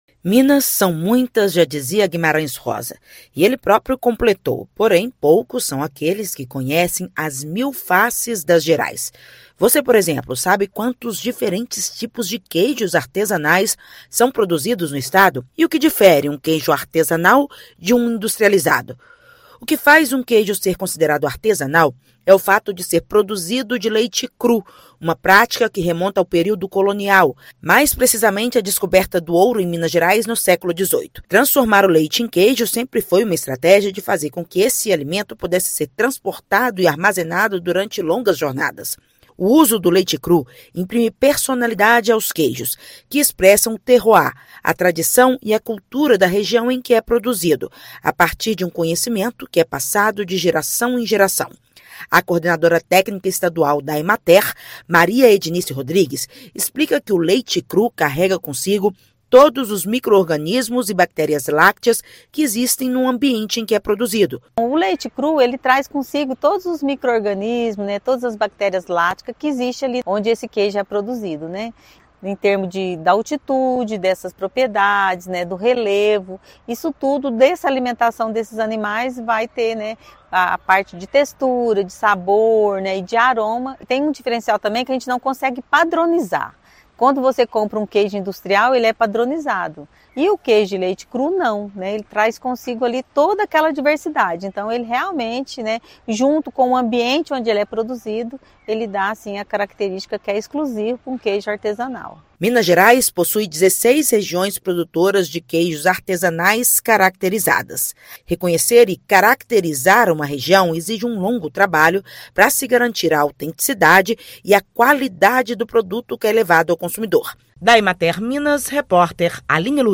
Estado produz qualidade e diversidade de iguarias que conquistam paladares. Ouça matéria de rádio.